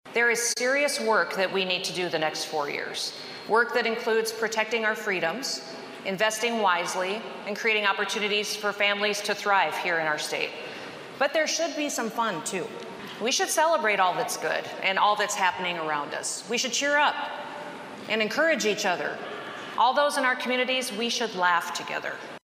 SOUTH DAKOTA GOVERNOR KRISTI NOEM TOOK HER OATH OF OFFICE ON SATURDAY TO BEGIN HER SECOND TERM.
DURING HER SPEECH, SHE SAID SHE LEARNED A LOT DURING HER FIRST FOUR YEARS IN OFFICE: